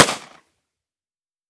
38 SPL Revolver - Gunshot B 004.wav